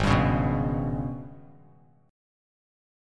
G#mM7bb5 Chord